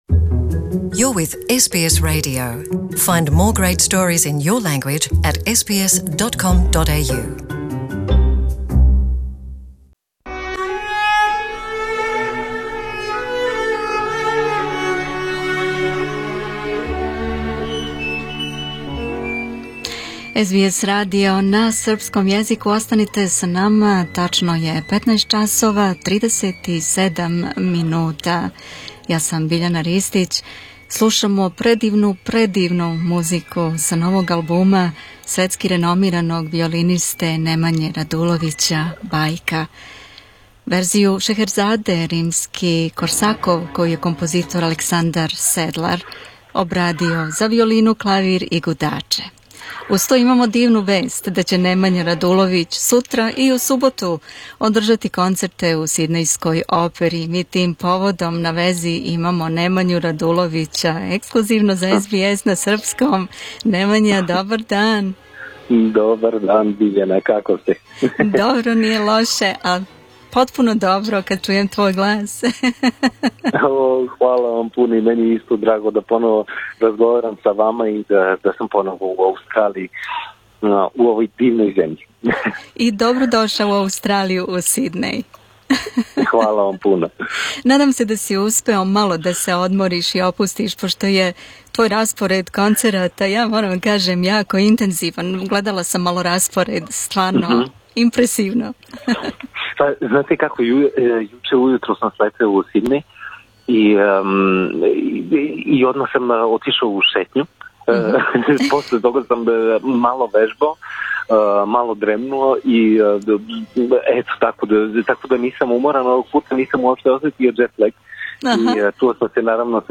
Nemanja Radulovic is in Sydney Opera House for two days only tomorrow and on Saturday. Nemanja has joined us live today in the program for a chat about the concerts and beyond ....